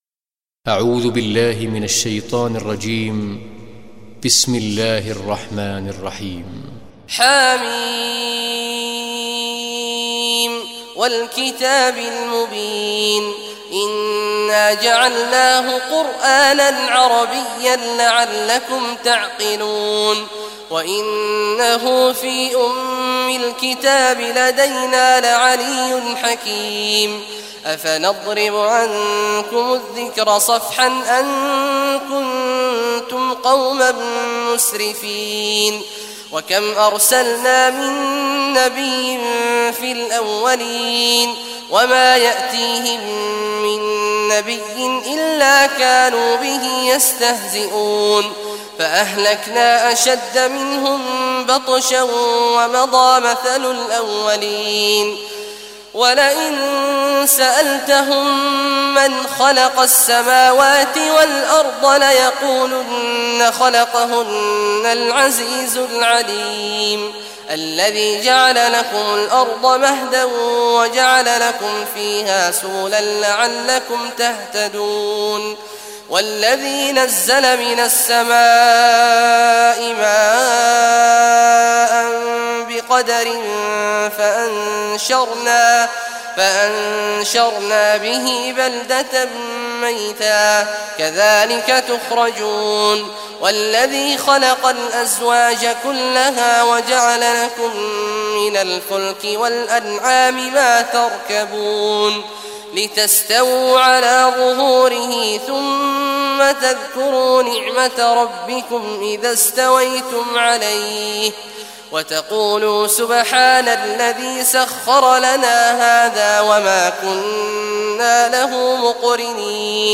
Surah Zukhruf Recitation by Sheikh Abdullah Juhany
Surah Az-Zukhruf, listen or play online mp3 tilawat / recitation in Arabic in the beautiful voice of Sheikh Abdullah Awad al Juhany. Download audio tilawat of Surah Az-Zukhruf free mp3 in best audio quality.